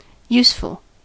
Ääntäminen
IPA : /ˈjuːsfəl/